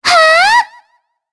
Shea-Vox_Casting3_jp.wav